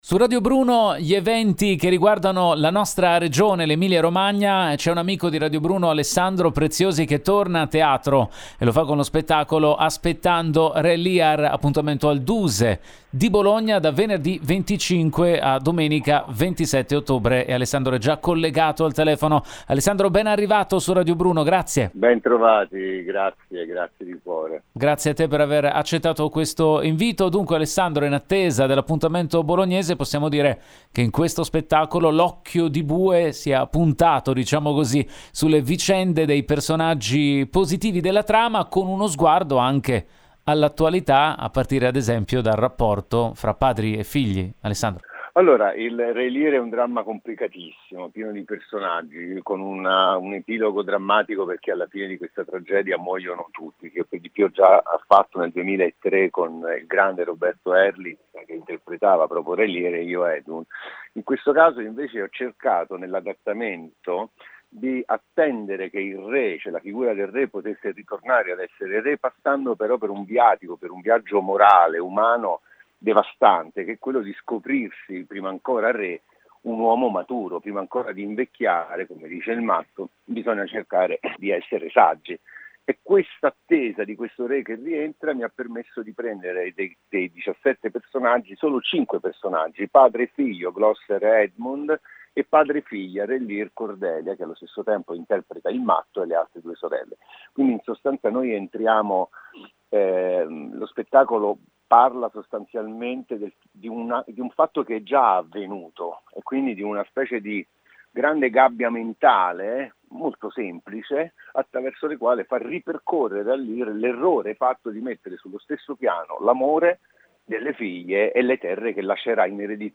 Home Magazine Interviste Alessandro Preziosi presenta “Aspettando Re Lear”